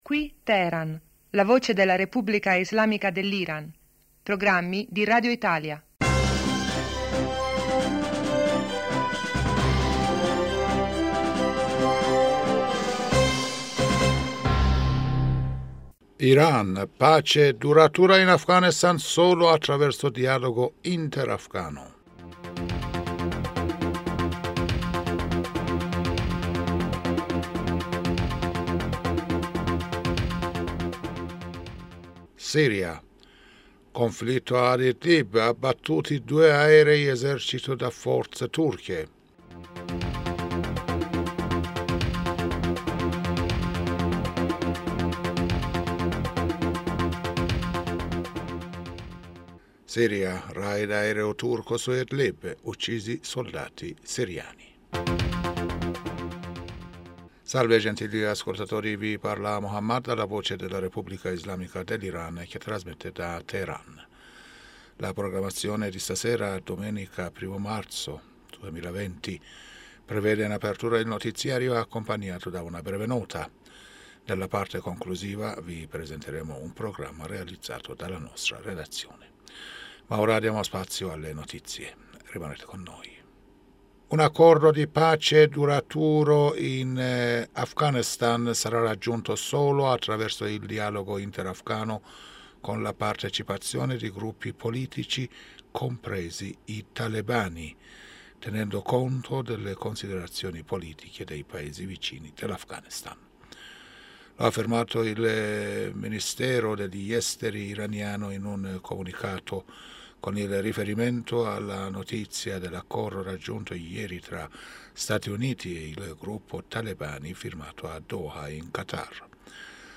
Giornale radio 2020-03-01 (sera)